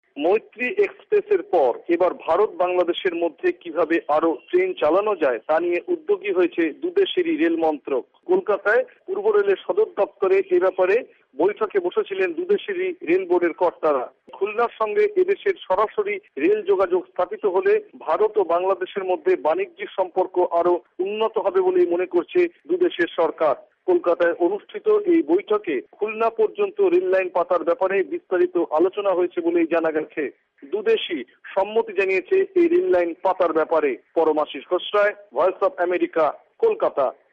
ভয়েস অব আমেরিকার কলকাতা সংবাদদাতাদের রিপোর্ট